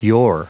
Prononciation du mot yore en anglais (fichier audio)
Prononciation du mot : yore